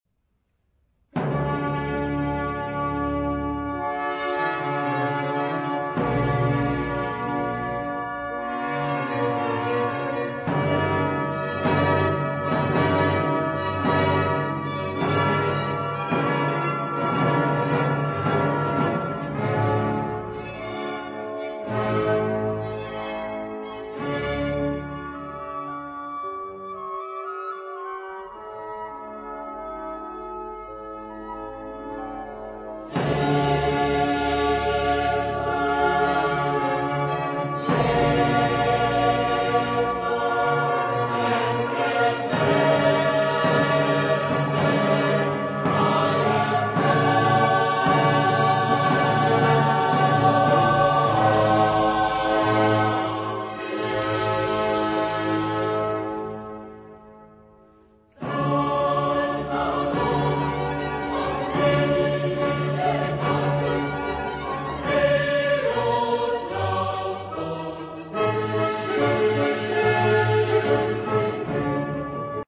Genre-Style-Forme : Sacré ; Classique ; Hymne (sacré)
Caractère de la pièce : majestueux ; adagio
Type de choeur : SATB  (4 voix mixtes )
Solistes : SATB  (4 soliste(s))
Instrumentation : Orchestre classique  (19 partie(s) instrumentale(s))
Instruments : Flûte (2) ; Hautbois (2) ; Basson (2) ; Cor (2) ; Clarine (2) ; Timbale (2) ; Trombone (3) ; Violon (2) ; Alto (div) ; Violoncelle et Contrebasse ; Orgue (1)
Tonalité : ré majeur